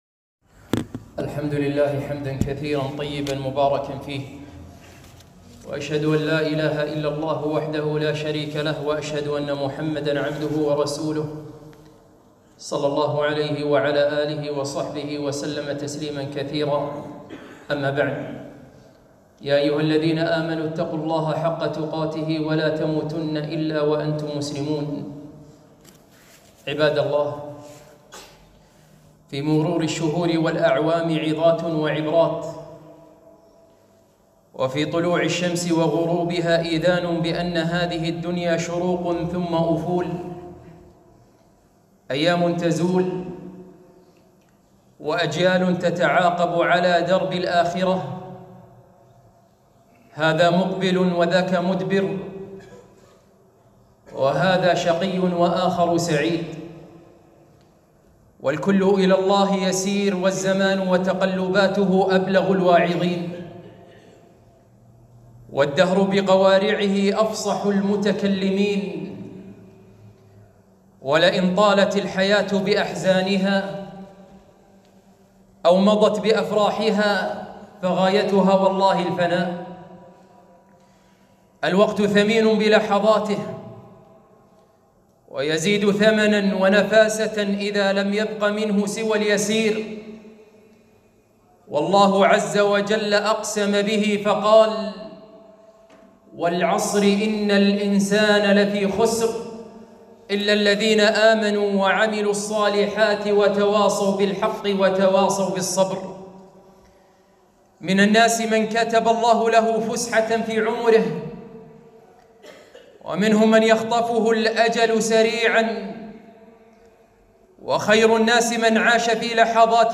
خطبة - هيا نقتل أوقات الفراغ !